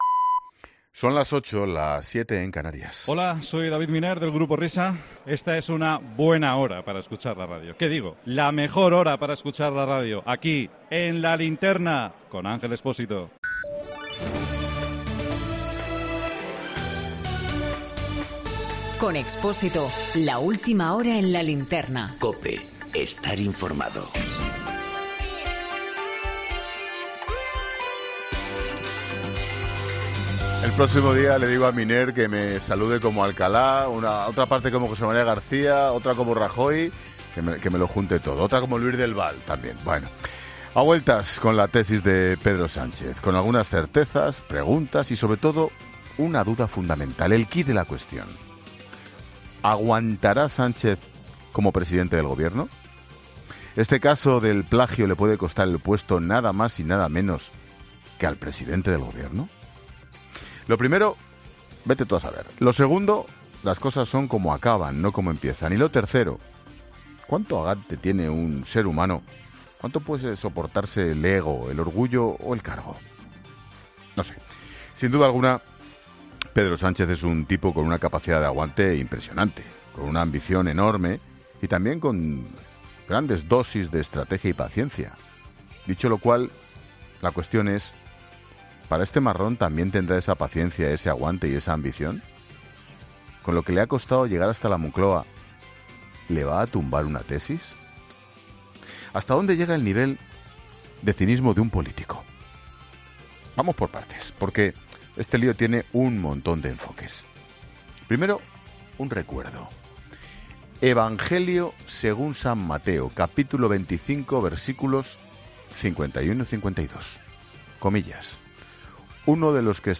Monólogo de Expósito
El análisis de actualidad de Ángel Expósito en 'La Linterna' a las 20h